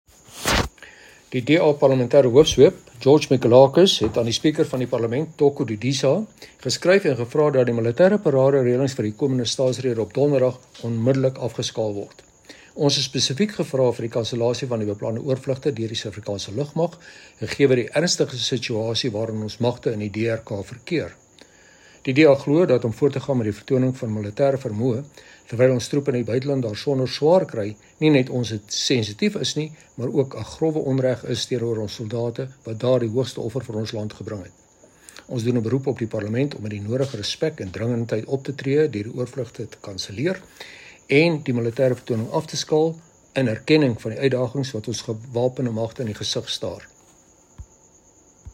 Afrikaans soundbites by Chris Hattingh MP.